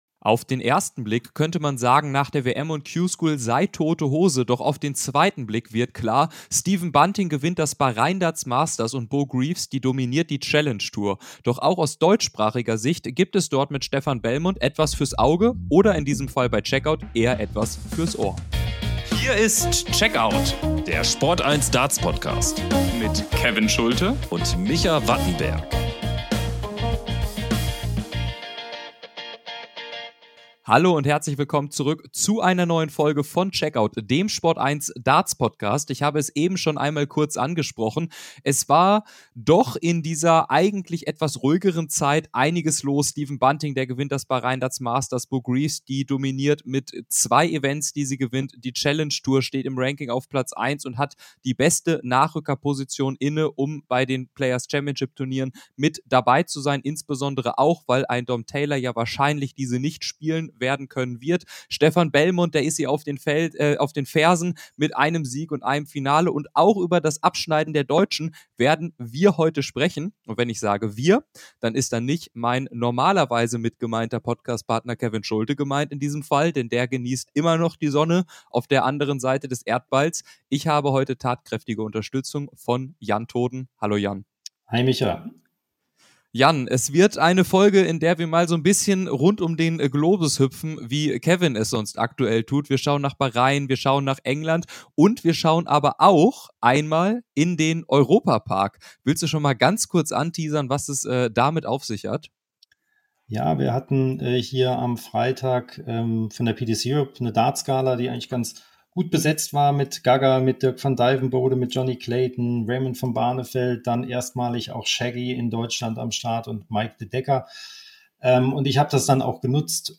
Außerdem haben wir Raymond van Barneveld und Gabriel Clemens im Rahmen der Europa Park Darts Gala vors Mikrofon gebeten, um ihre Aussagen zu den neuen European Tour Regelungen zu erfahren.